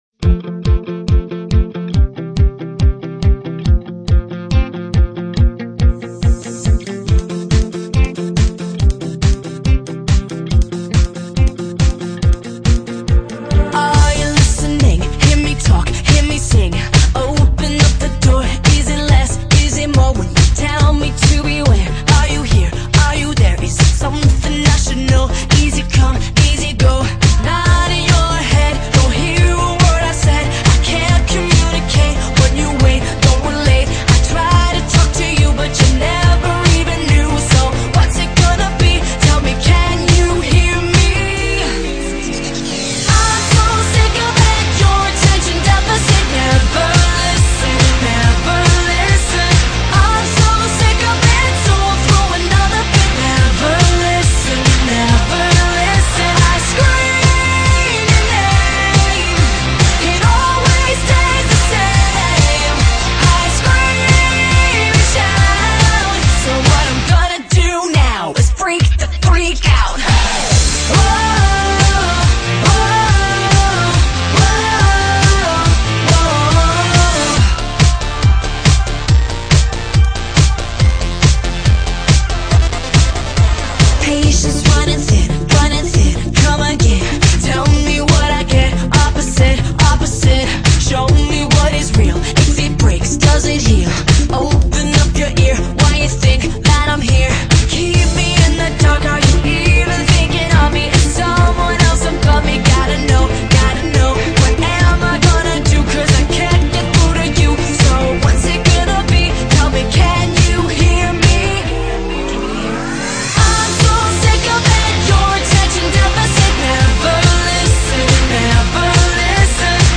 GÊNERO: POP DANCE